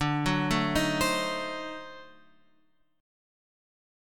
D 7th Flat 9th